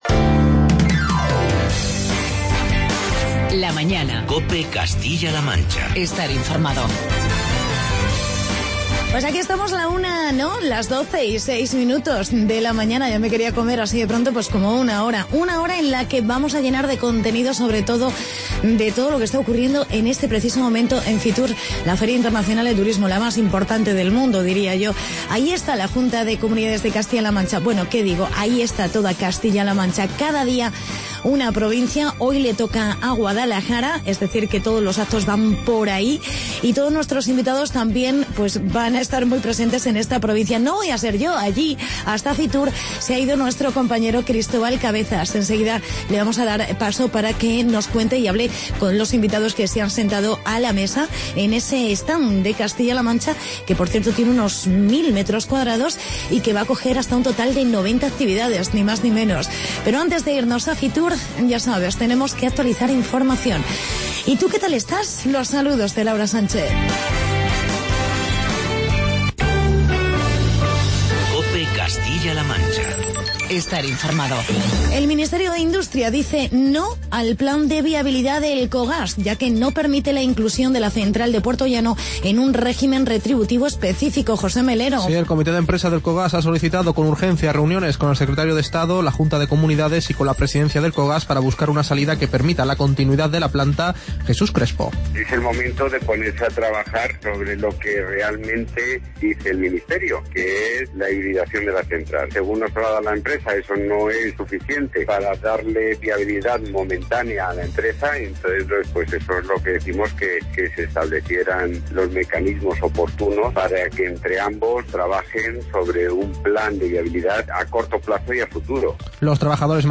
Programa especial Fitur